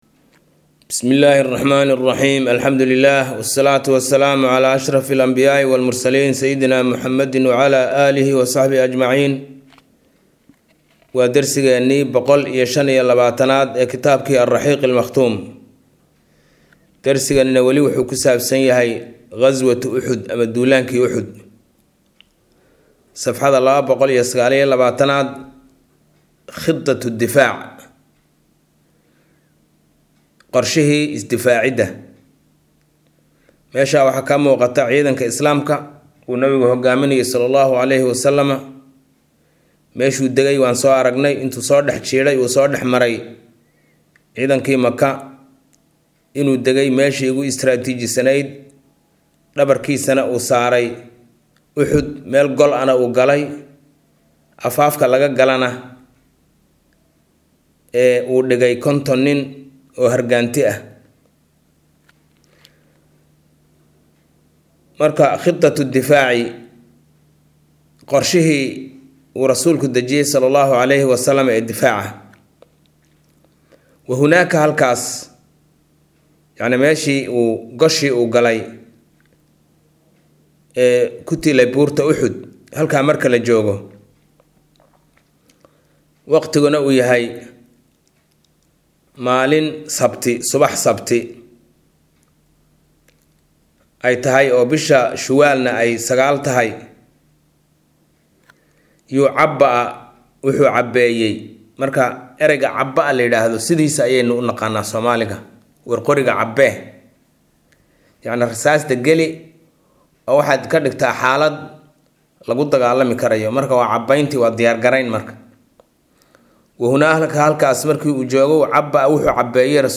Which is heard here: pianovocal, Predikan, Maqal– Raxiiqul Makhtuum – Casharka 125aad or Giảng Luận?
Maqal– Raxiiqul Makhtuum – Casharka 125aad